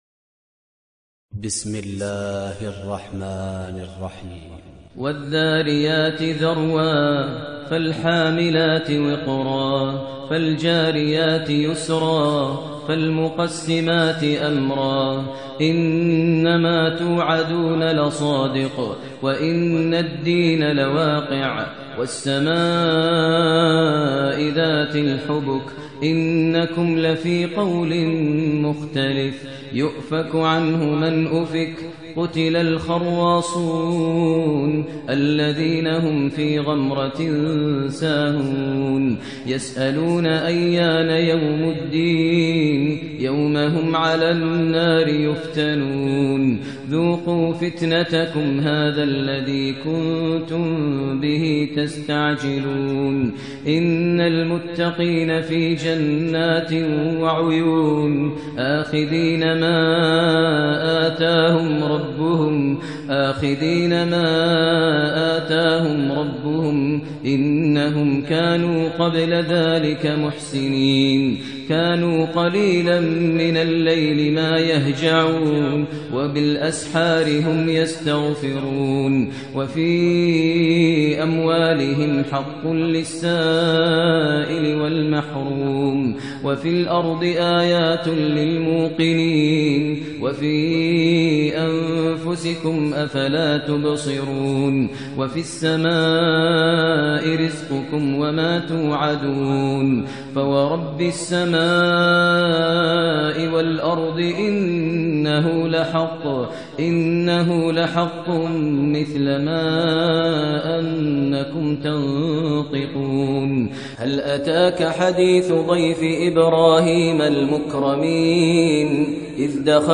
ترتیل سوره ذاریات با صدای ماهر المعیقلی